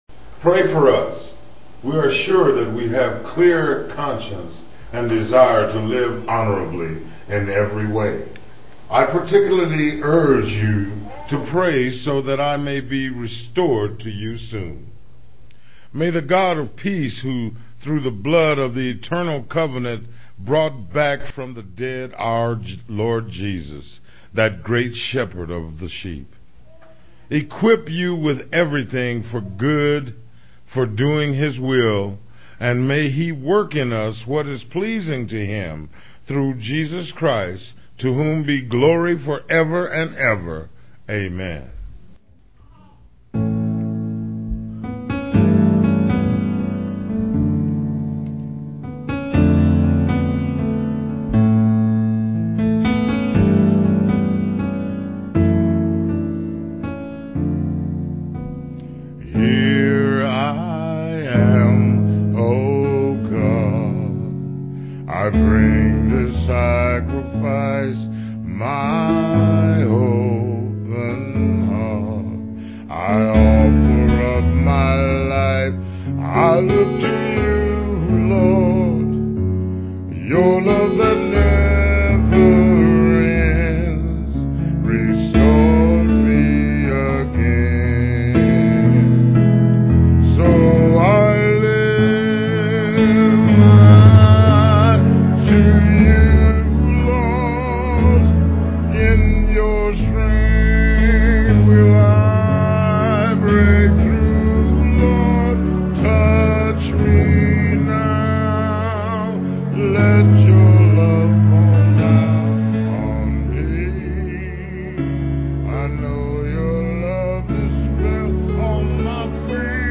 PLAY 10 Commandments for the Home, 8, Jun 25, 2006 Scripture: Hebrews 13:17-21. Scripture reading